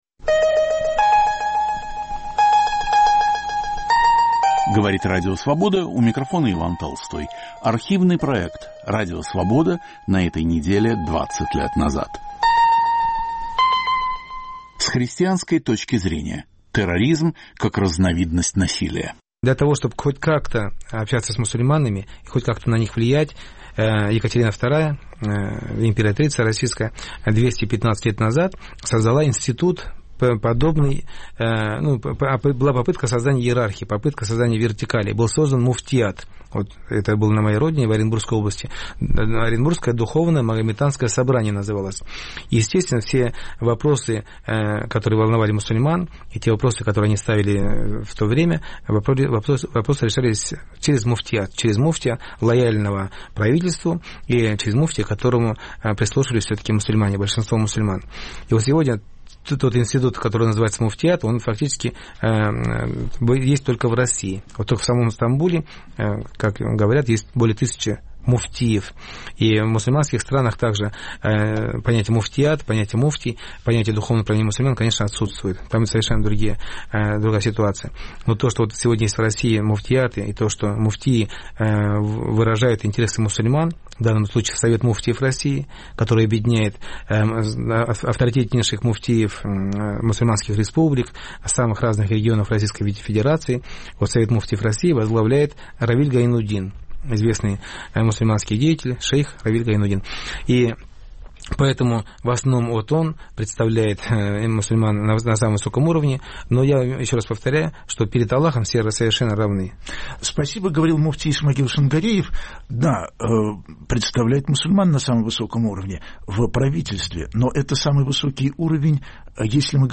Об отношении христианства к терроризму, прежде всего, к исламскому. В передаче участвуют мусульмане и христиане.